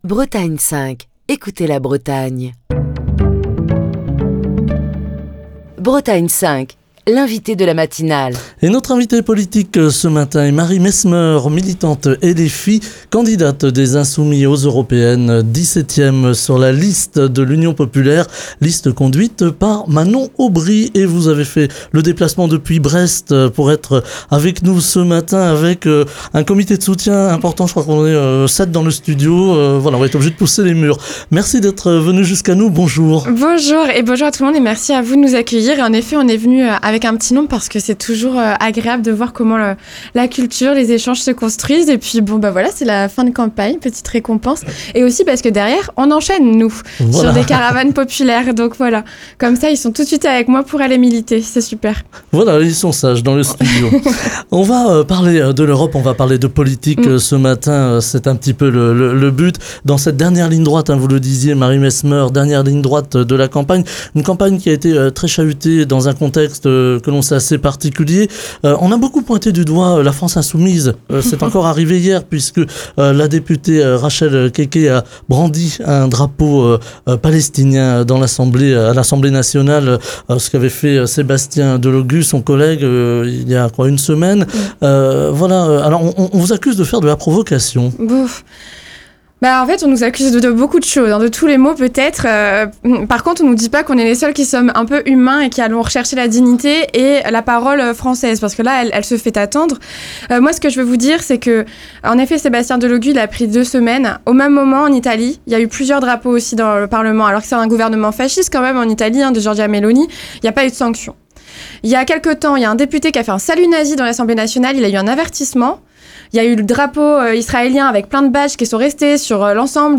Écouter Télécharger Partager le podcast Facebook Twitter Linkedin Mail L'invité de Bretagne 5 Matin